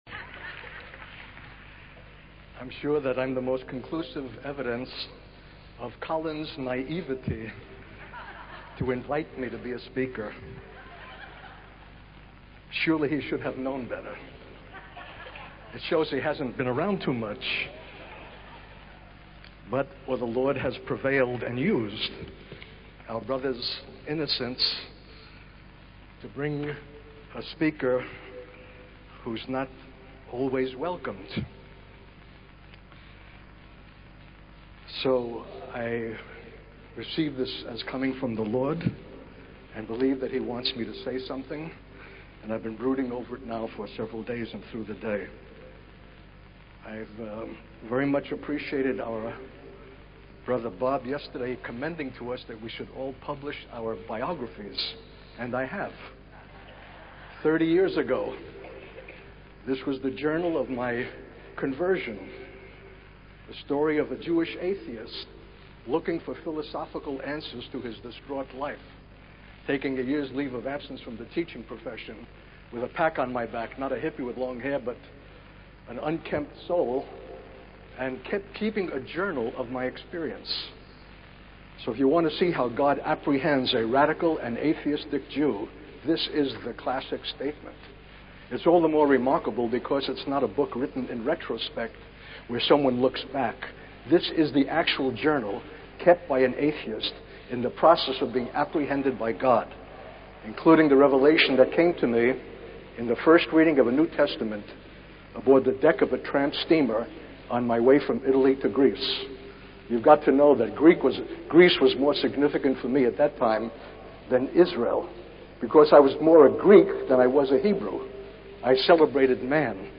In this sermon, the speaker shares a personal experience of witnessing the slaughter of a pig and a lamb side by side. This experience taught the speaker about the importance of true conversion and the need for believers to respond to God's call with genuine repentance and surrender.